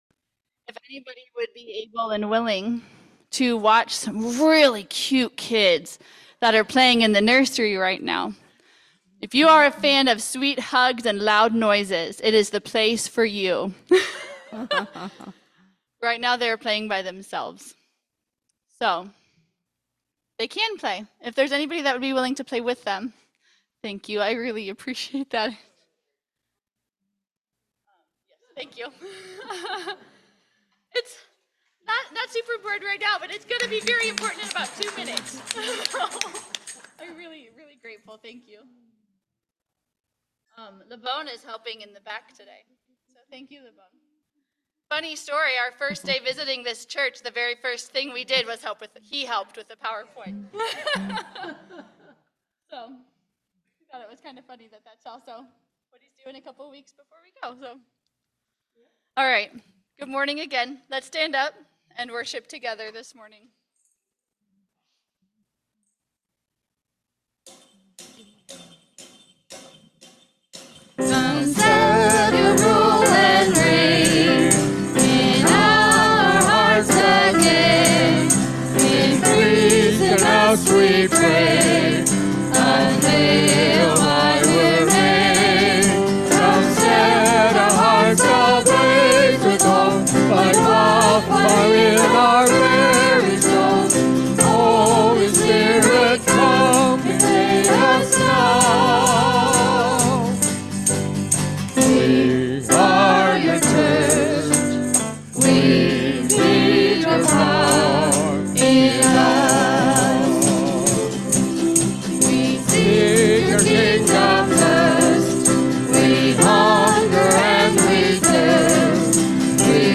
Worship-August-3-2025-Voice-Only.mp3